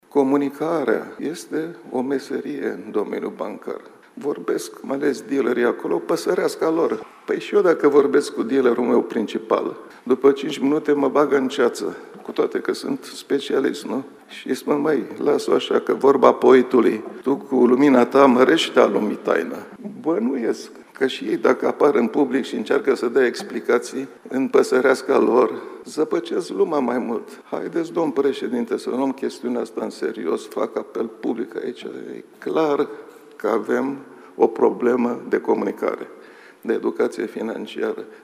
Guvernatorul Băncii Naționale a vorbit azi despre confuziile din societate legate de politicile monetare, la Forumul Educației Financiare, și s-a referit la raportul Consiliului Concurenței referitor la modul în care s-a stabilit indicele ROBOR – dobânda pentru cei care aveau credite în lei, înlocuit acum cu IRCC.
Mugur Isărescu a cerut președintelui Nicușor Dan, prezent la dezbatere, să considere educația financiară o prioritate strategică pentru publicul larg.